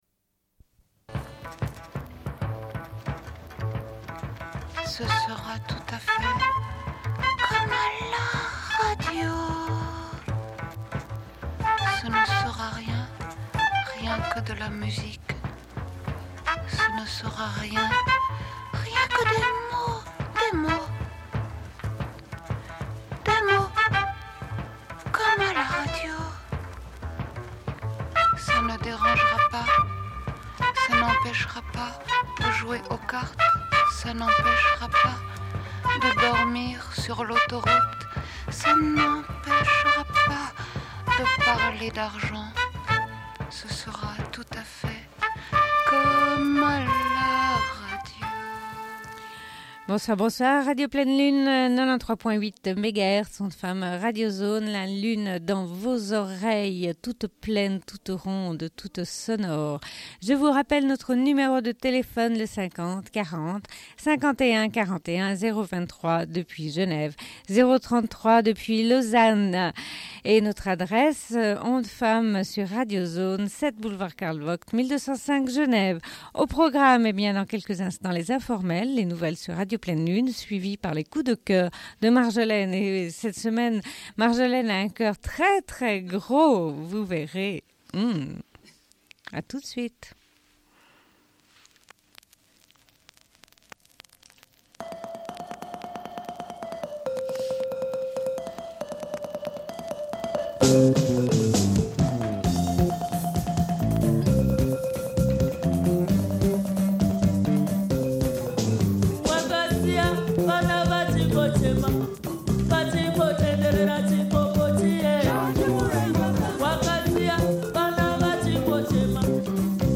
Une cassette audio, face B30:21